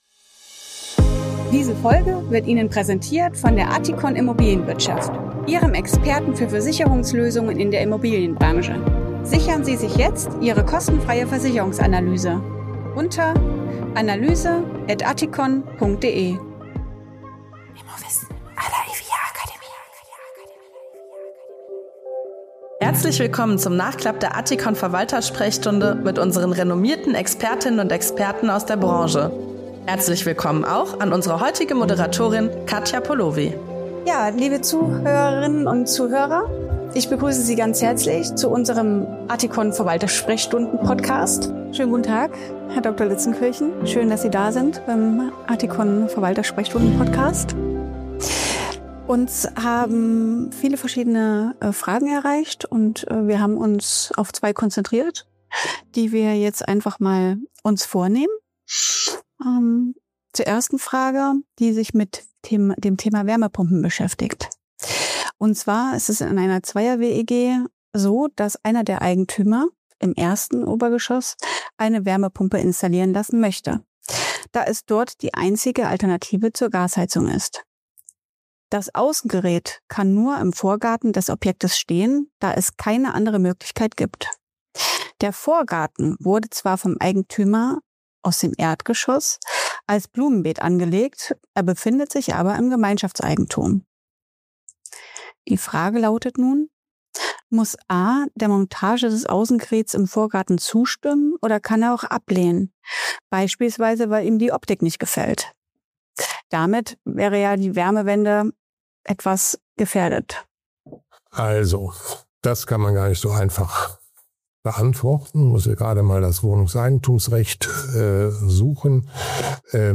Expertenrunde in dieser Folge: